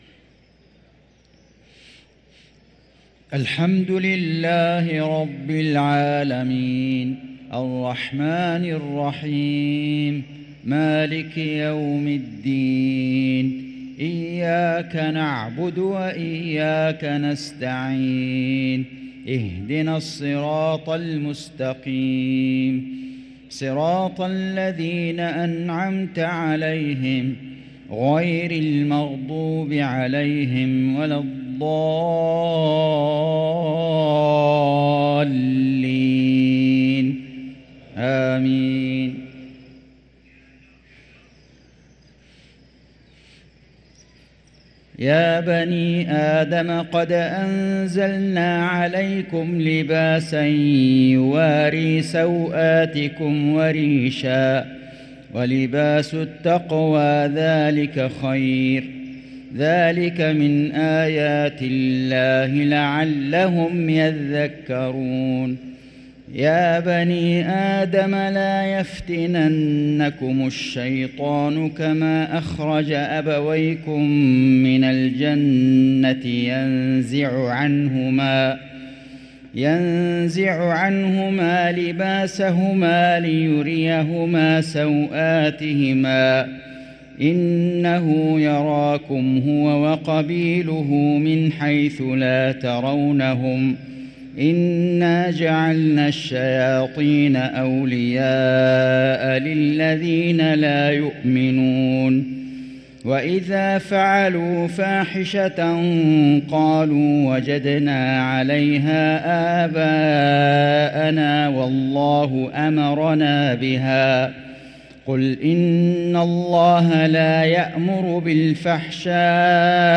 صلاة المغرب للقارئ فيصل غزاوي 7 رمضان 1444 هـ
تِلَاوَات الْحَرَمَيْن .